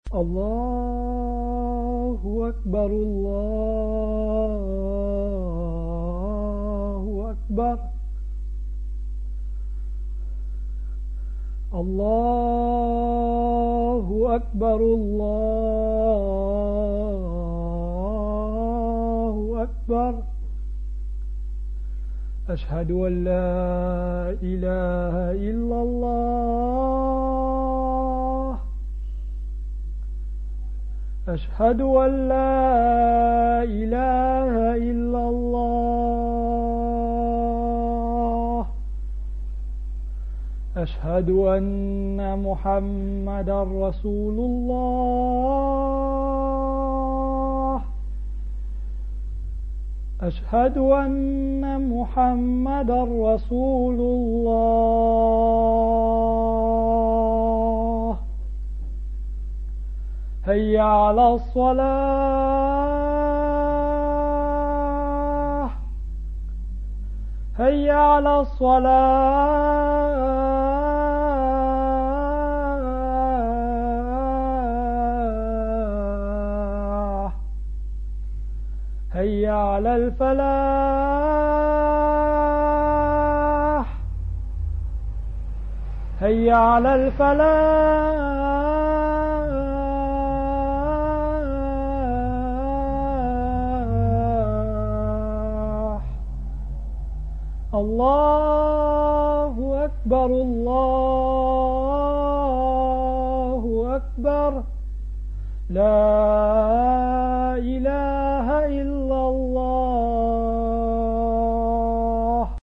ADHAAN - CALL TO PRAYER
When the time for any of the 5 obligatory prayers comes, a man (called a mu-adh-dhin) and calls aloud these words to summon muslims in the neighbourhood of the mosque to come to prayer: